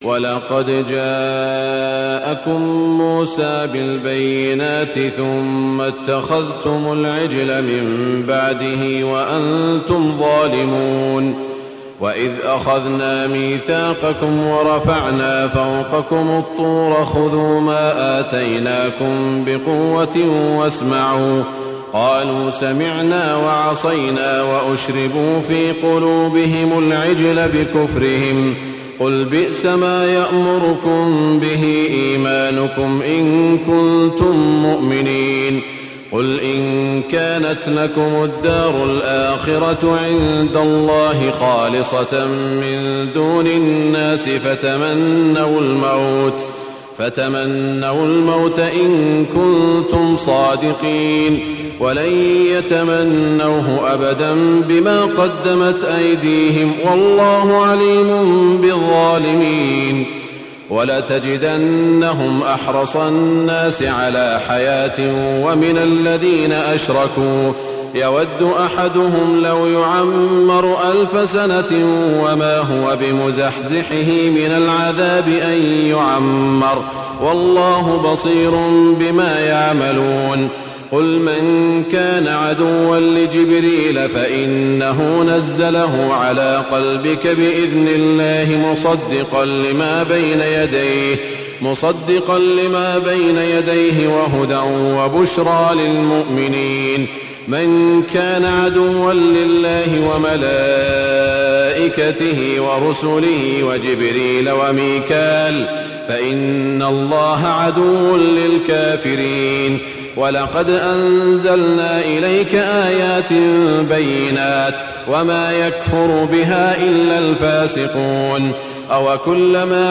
تهجد ليلة 21 رمضان 1423هـ من سورة البقرة (92-141) Tahajjud 21 st night Ramadan 1423H from Surah Al-Baqara > تراويح الحرم المكي عام 1423 🕋 > التراويح - تلاوات الحرمين